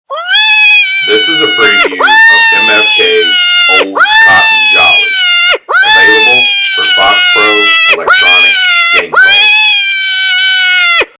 Recorded with the best professional grade audio equipment MFK strives to produce the highest
quality wildlife audio available to hunters in today’s market.